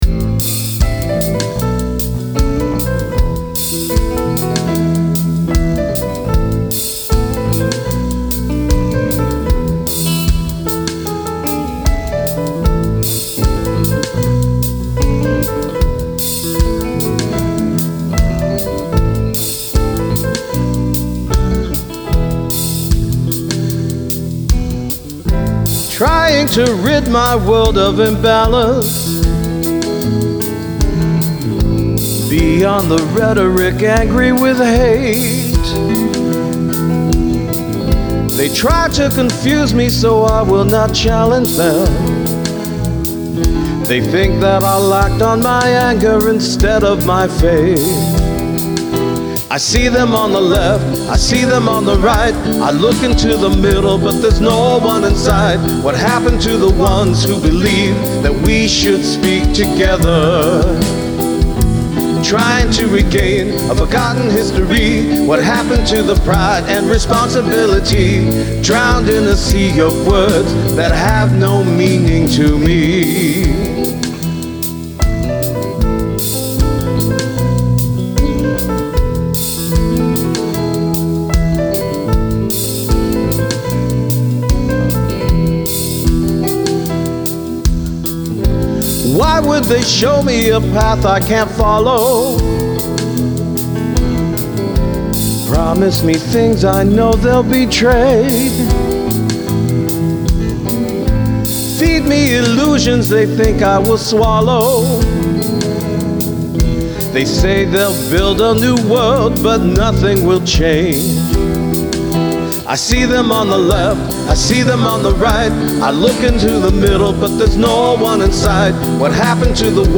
There is something about that vintage Marshall sound that just speaks “rock and roll” to me, so re-recorded the track with that setup.
For me, this is a finished demo!
The song started out as a straight-ahead reggae tune, but there was a huge part of me that wanted to maintain my hard rock roots, so I added a distorted guitar to the last third of the song.
So I switched amps altogether, and used my venerable Aracom VRX22, which is a Blues Breaker Plexi-style amp with 6V6’s instead of EL84’s. I strummed one chord, and that was it.